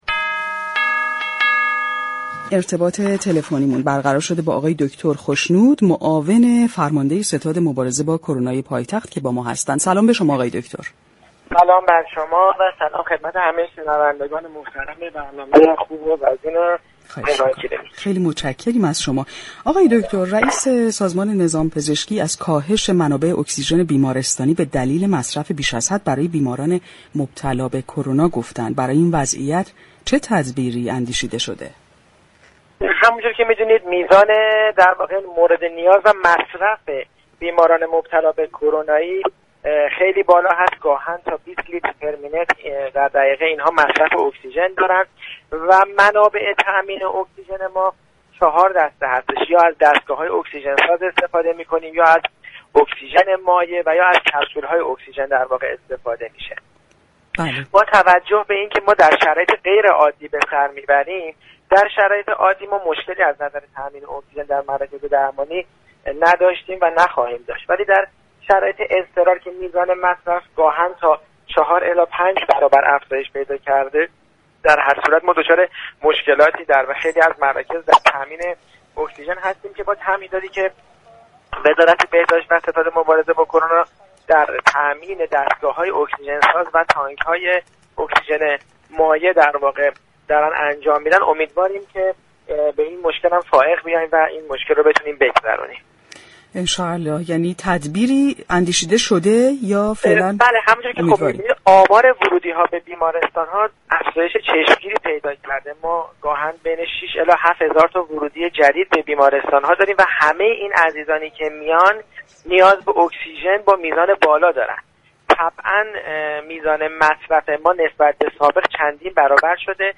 به گزارش پایگاه اطلاع رسانی رادیو تهران، رضا جلیلی خشنود در گفتگو با برنامه تهران كلینیك درباره كاهش منابع اكسیژن بیمارستانی گفت: میزان مورد نیاز و مصرف اكسیژن توسط بیماران كرونایی بسیار بالا است و گاهاً به 20 لیتر در دقیقه می رسد.